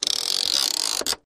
Baby Toy Slider